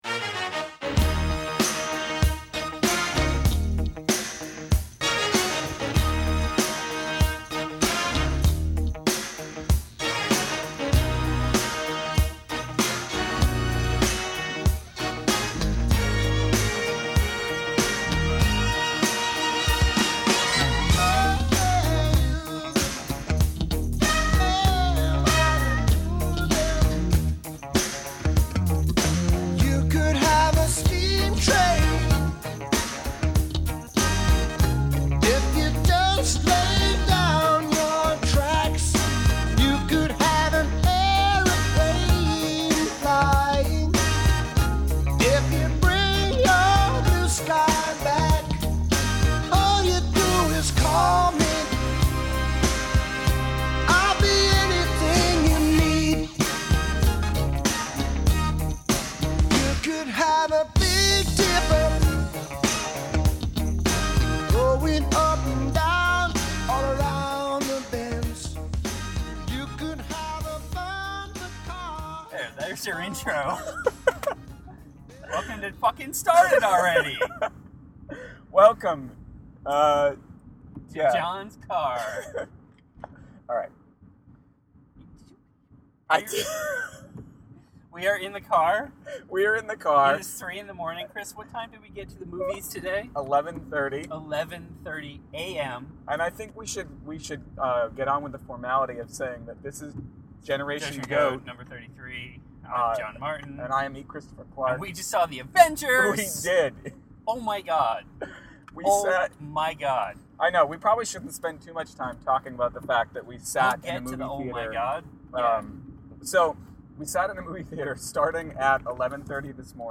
We recorded in my car on the way home, and we might have been just a bit wired from spending the whole day in a movie theater watching comic book movies.